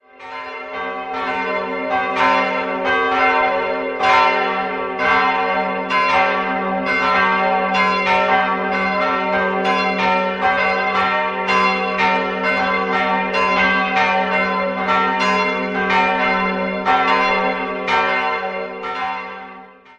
Jahrhundert. 3-stimmiges TeDeum-Geläute: g'-b'-c'' Die Glocken 1 und 3 wurden 1954 von Friedrich Wilhelm Schilling gegossen, die mittlere ist ein Werk von Mathias Stapf (Eichtätt) aus dem Jahr 1782.